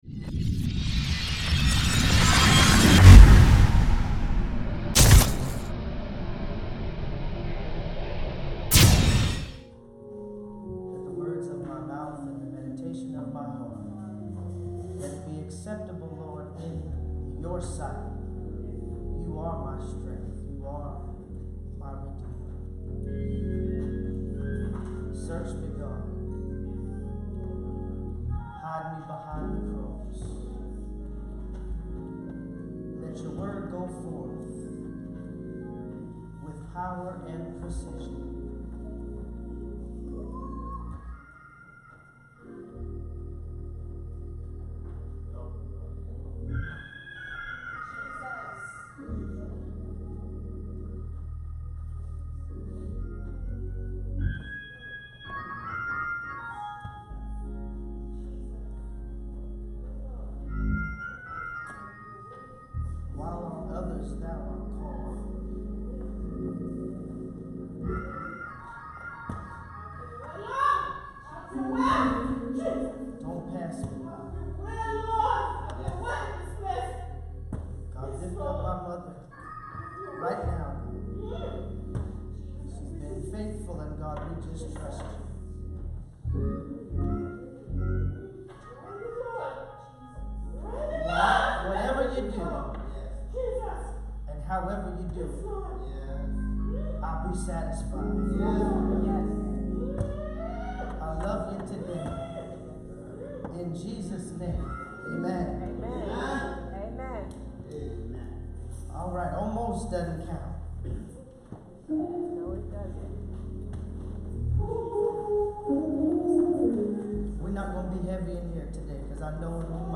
Sermons - Restoration Temple